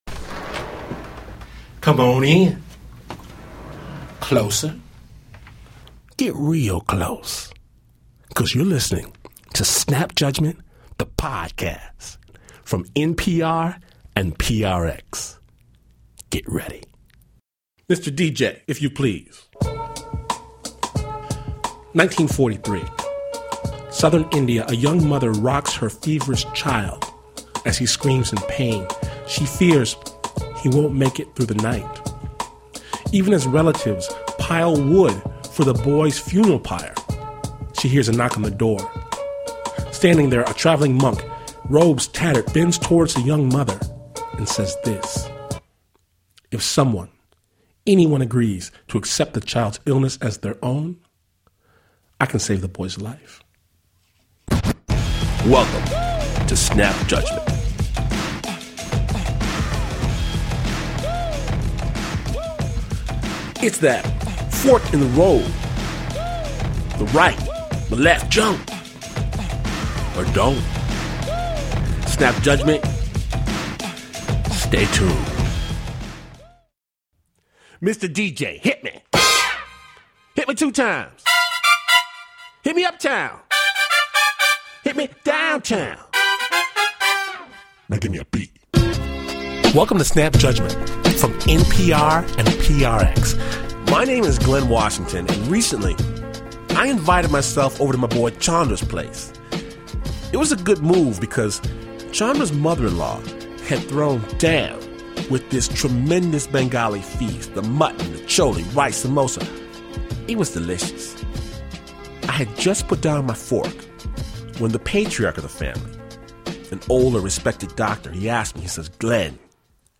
Snap Judgment bumps up against the inexplicable as storytelling with a beat takes a magic ride through India, Morocco, Indonesia, Senegal and the good ol' Midwest.